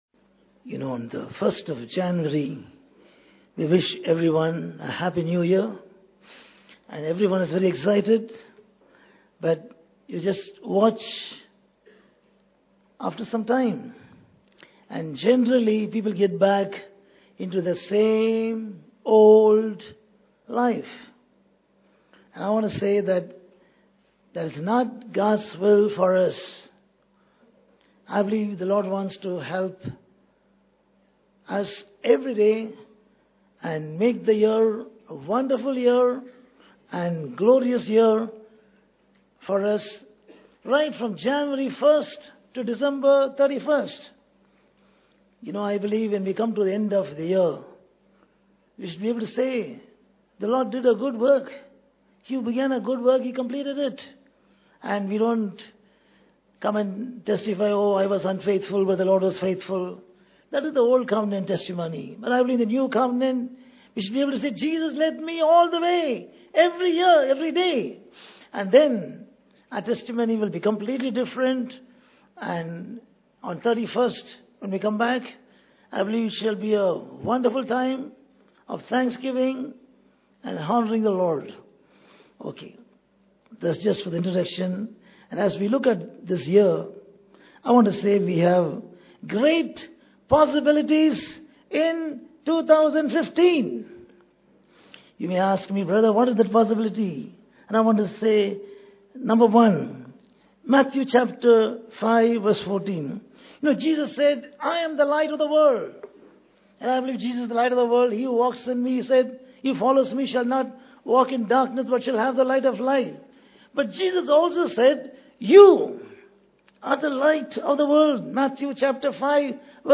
The Church Dedication Service